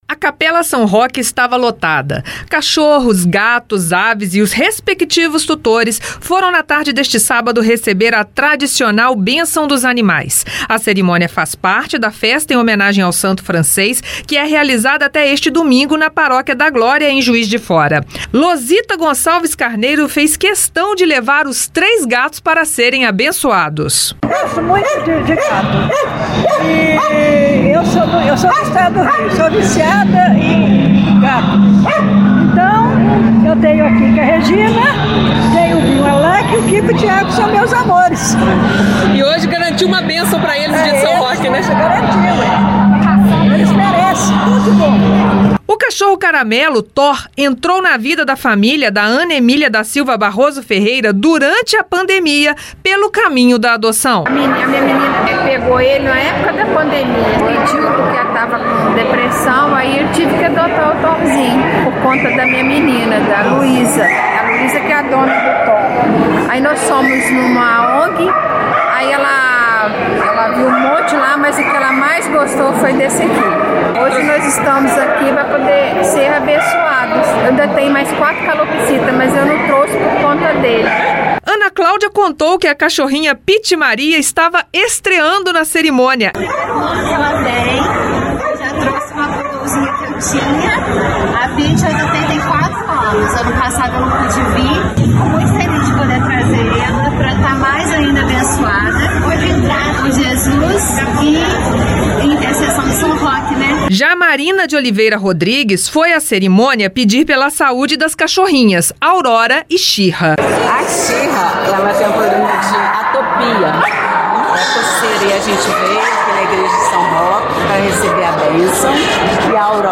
A Itatiaia acompanhou a cerimônia e registrou histórias de amor entre tutores e bichinhos.